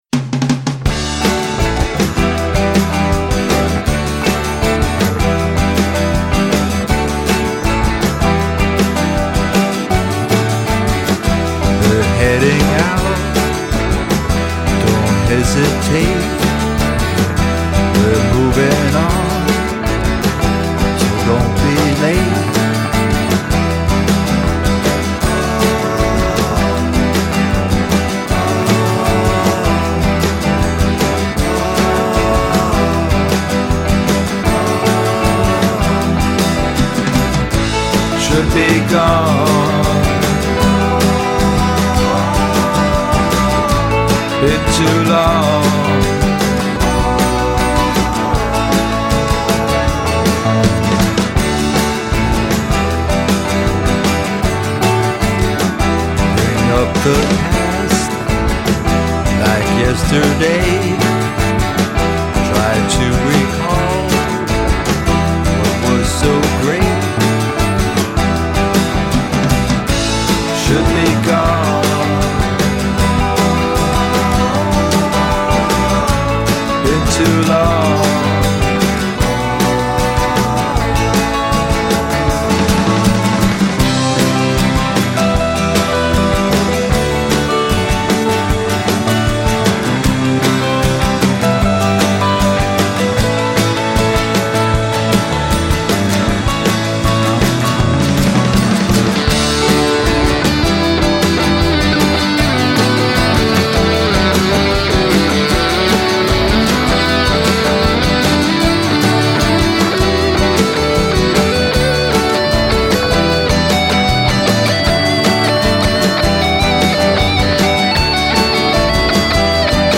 ha un umore molto sereno e rilassato, anche se va veloce